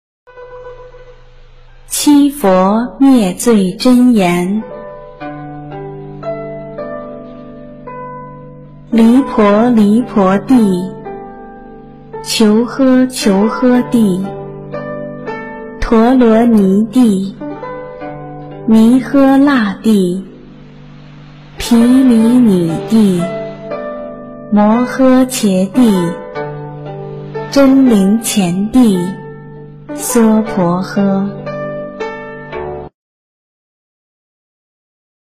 《七佛灭罪真言》中文·最美大字拼音经文教念